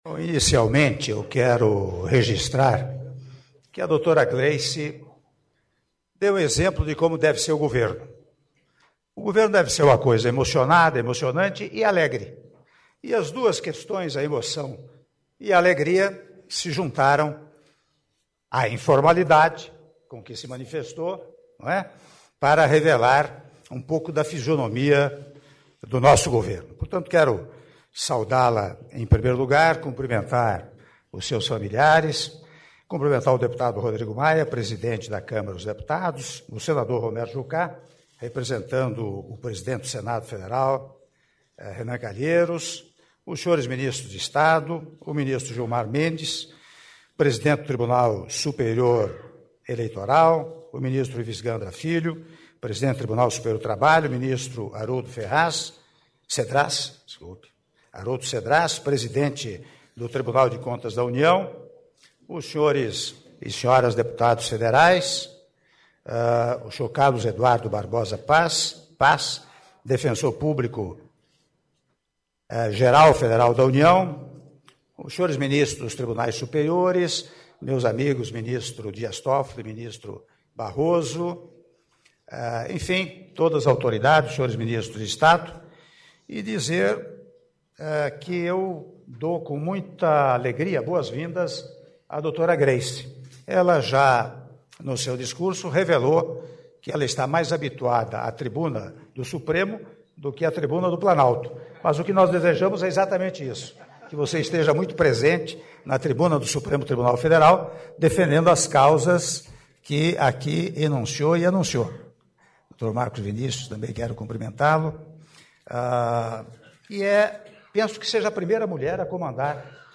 Áudio do discurso do Senhor Presidente da República, Michel Temer, na cerimônia de posse da Senhora Grace Maria Fernandes Mendonça no cargo de Advogada-Geral da União- Brasília/DF- (06min52s)